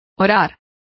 Complete with pronunciation of the translation of pray.